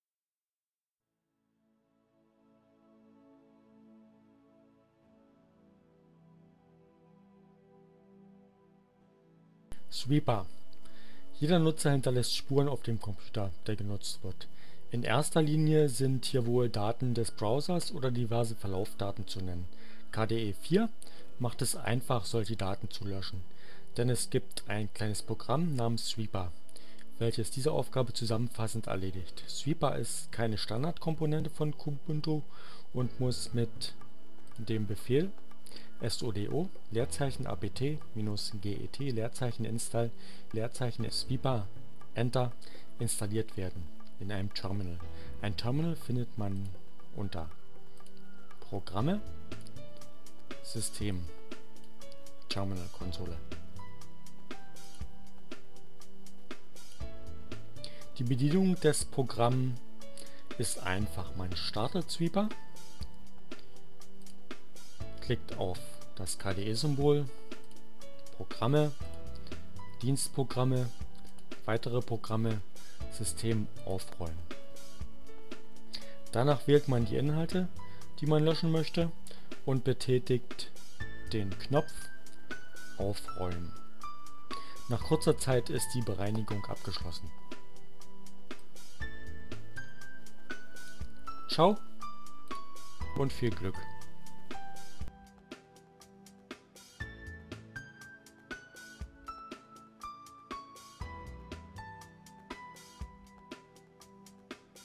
Tags: CC by-sa, KDE, Linux, Neueinsteiger, Ogg Theora, ohne Musik, screencast, Kubuntu, sweeper